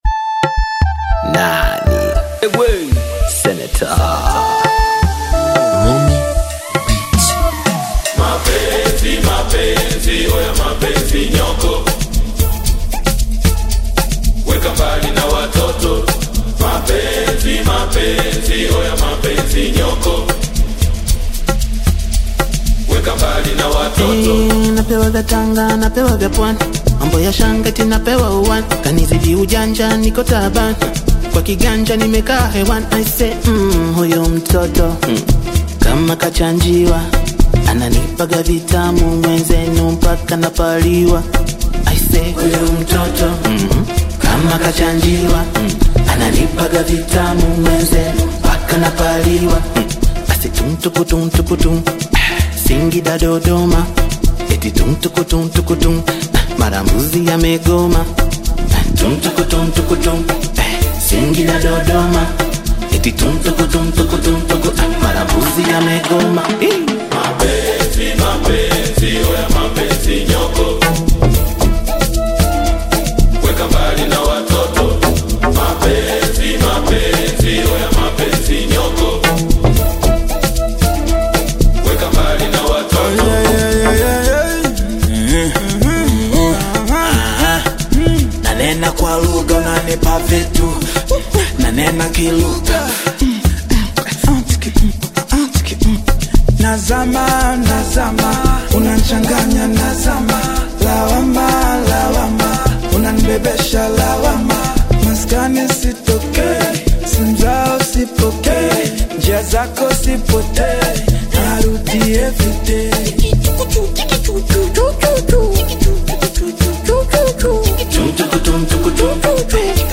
a captivating blend of Bongo Flava and Afrobeat vibes